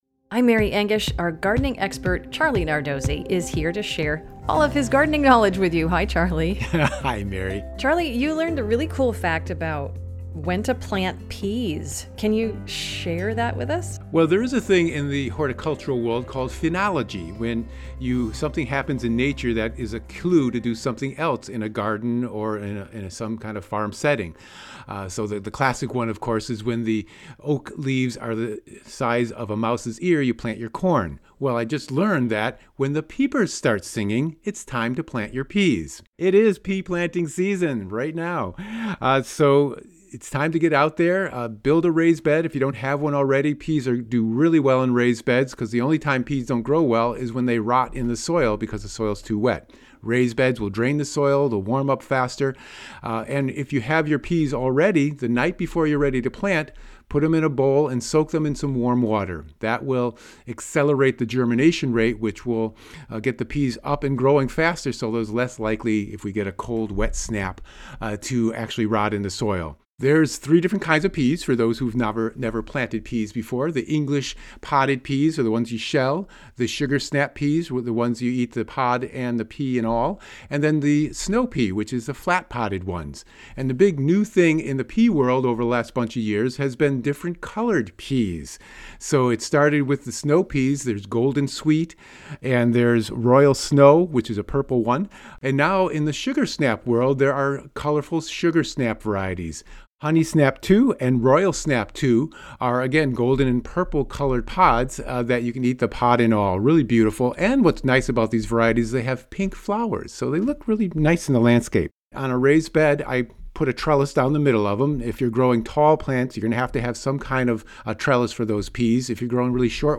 a conversation about gardening